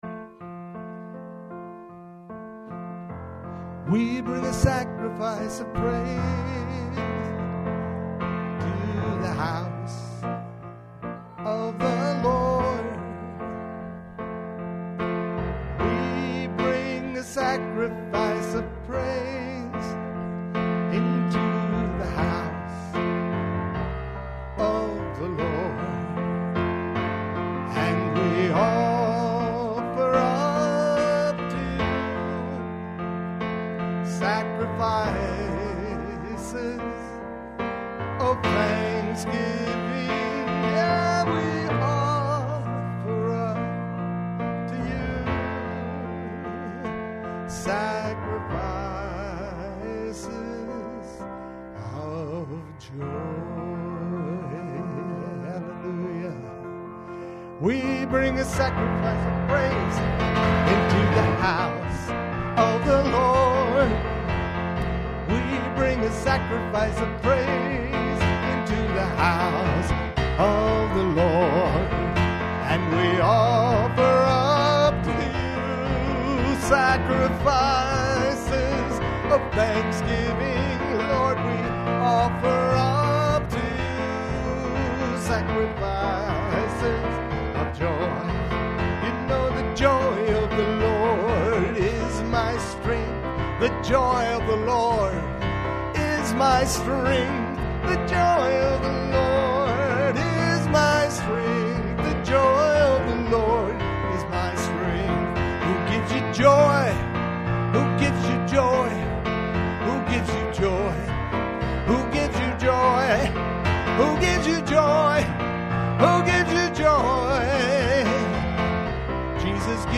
WORSHIP 1026.mp3